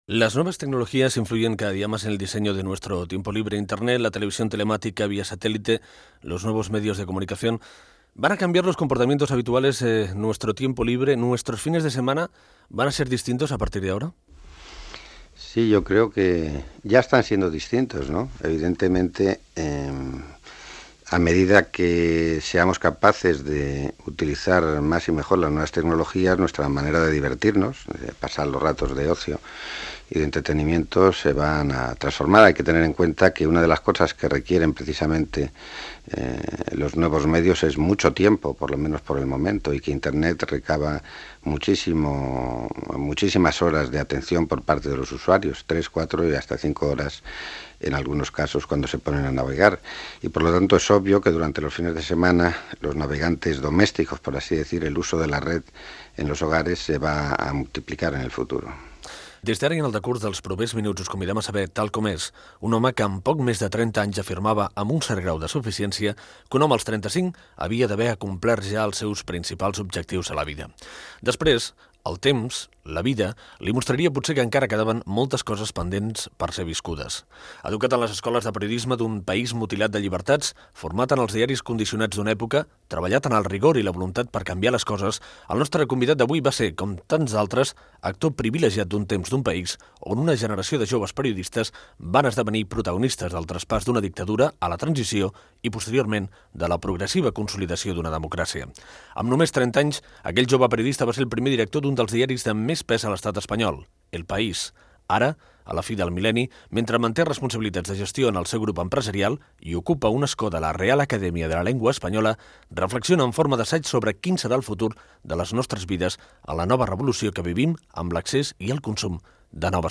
Fragment d'una entrevista al periodista Juan Luis Cebrián, conseller delegat del grup Prisa.